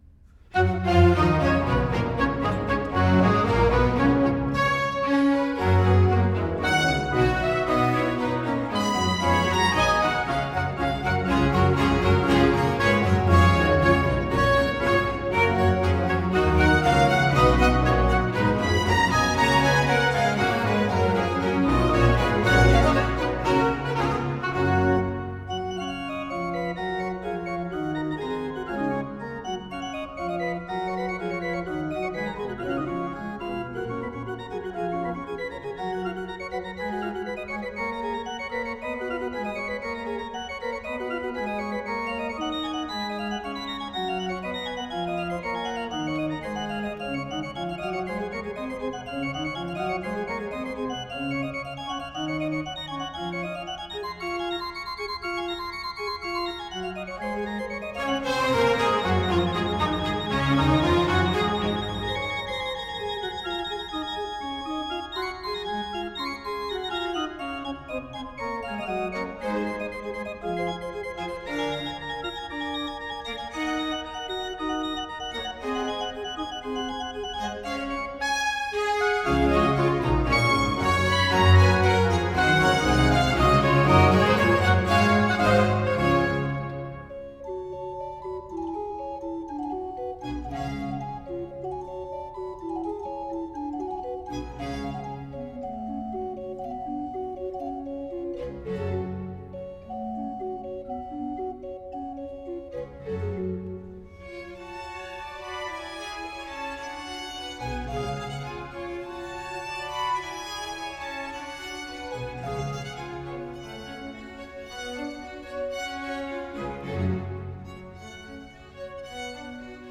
Классическая Музыка
Organ Concerto in G minor